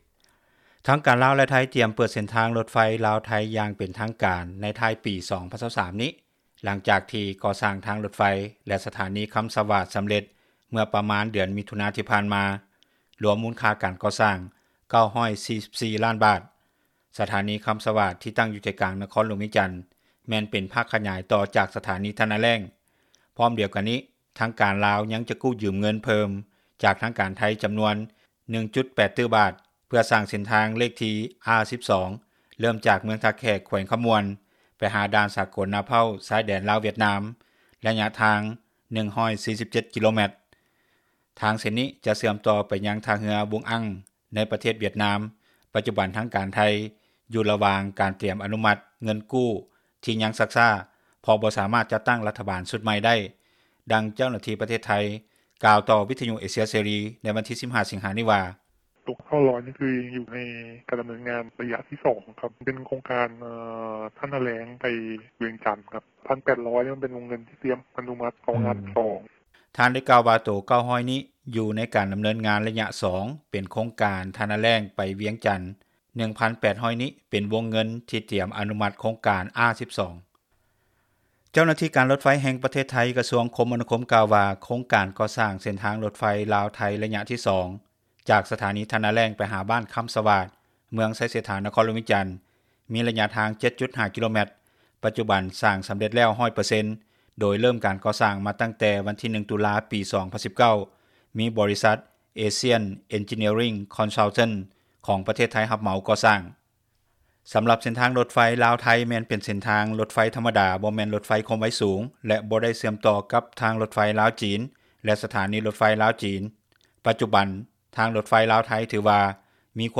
ດັ່ງເຈົ້າໜ້າທີ່ ປະເທດໄທຍ ກ່າວຕໍ່ວິທຍຸເອເຊັຽ ເສຣີ ໃນວັນທີ 15 ສິງຫາ ນີ້ວ່າ:
ດັ່ງຊາວລາວຜູ້ນີ້ ກ່າວຕໍ່ວິທຍຸ ເອເຊັຽເສຣີ ໃນມື້ດຽວກັນນີ້ວ່າ: